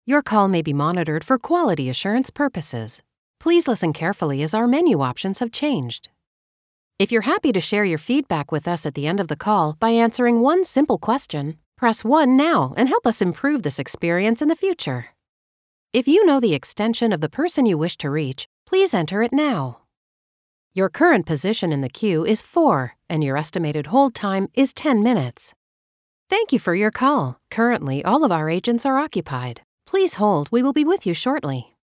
Phone-Messaging-&-IVR